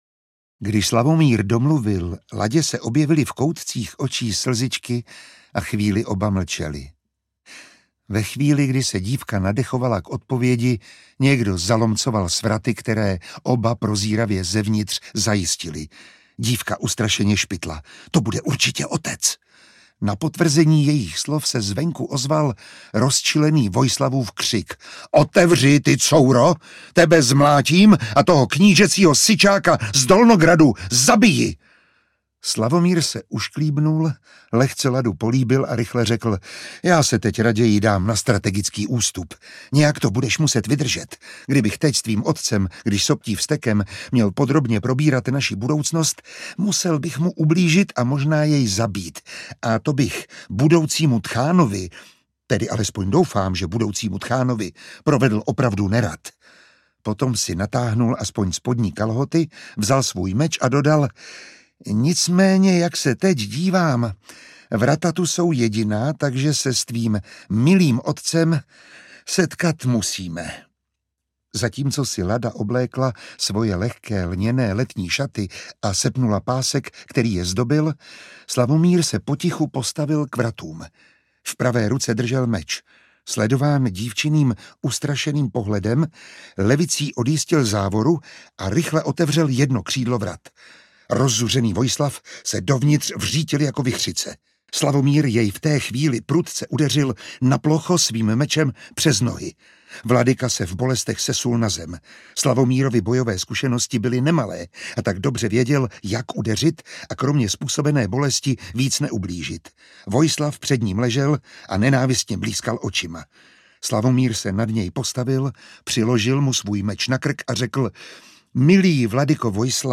Případ úzké dýky audiokniha
Ukázka z knihy
Čte Miroslav Táborský.